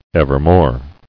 [ev·er·more]